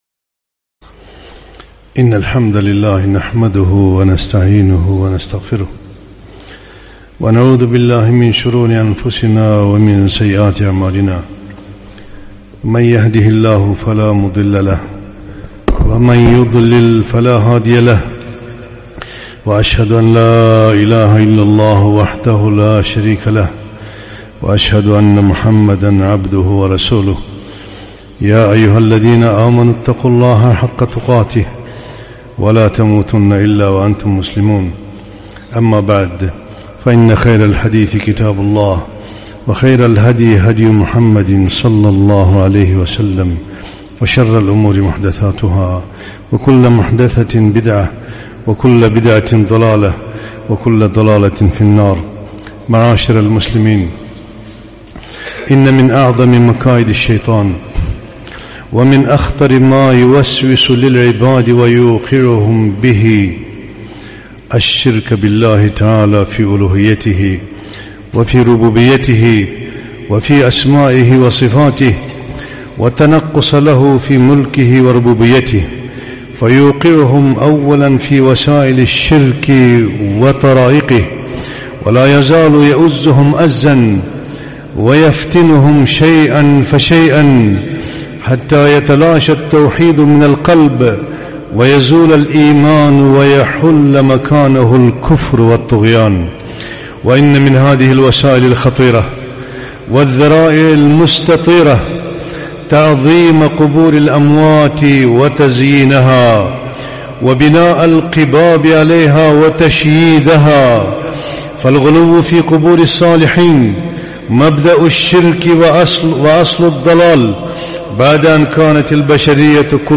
خطبة - آداب المقابر